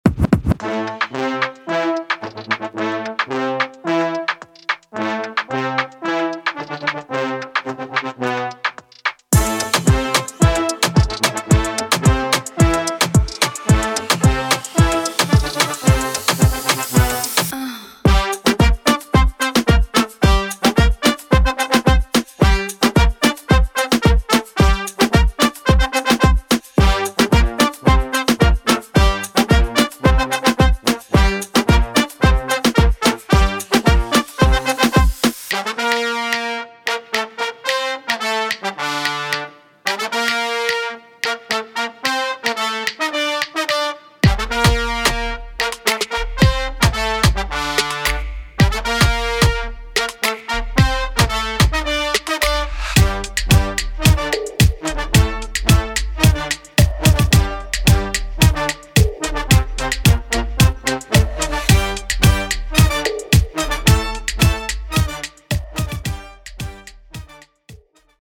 trombone
trumpet
Expertly recorded, beautiful in tone.